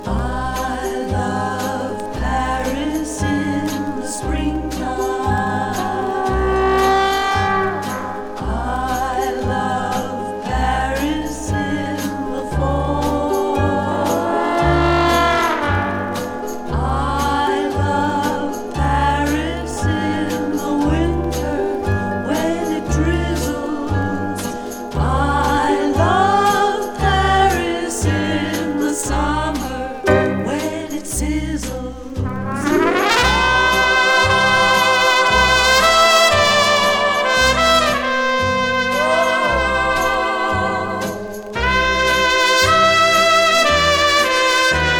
Jazz, Easy Listening　USA　12inchレコード　33rpm　Mono